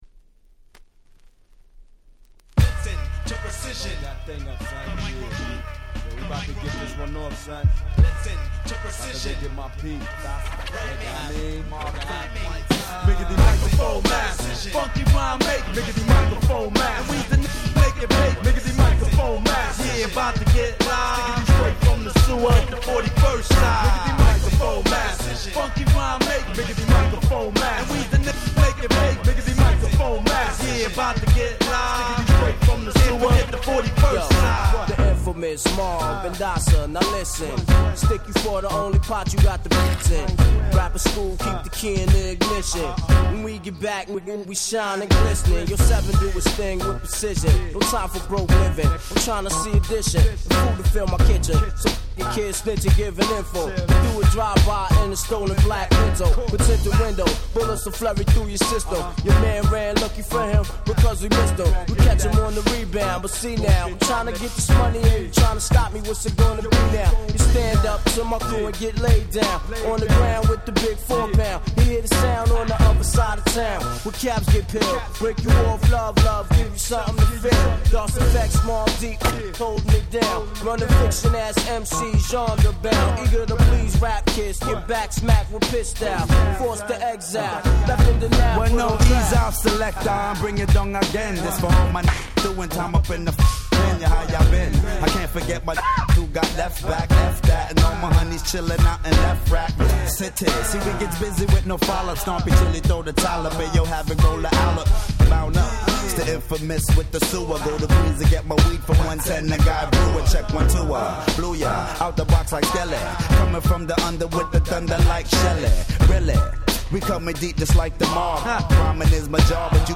95' Smash Hit Hip Hop !!